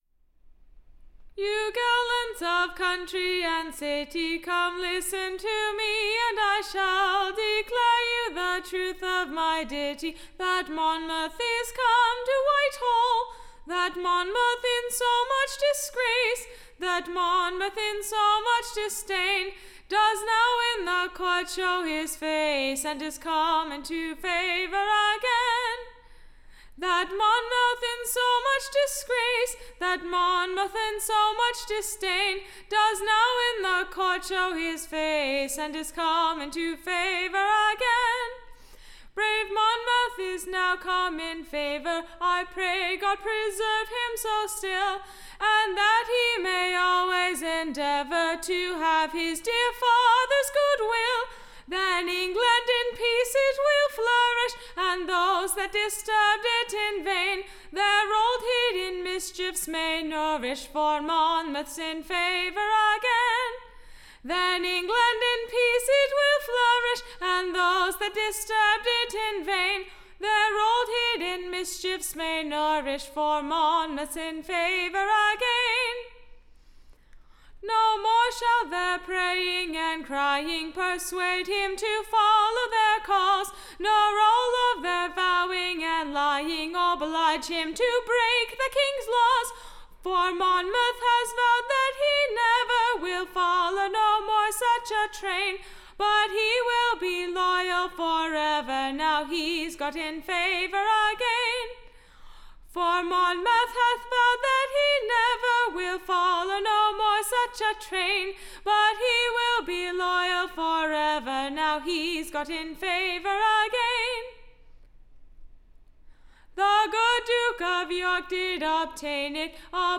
Recording Information Ballad Title The Merciful Father, or, The Penitent Son; / A Congratulatory SONG on the Happy and most wish'd for Return of / James D. of Monmouth / To COURT; and his Reception into Favour again.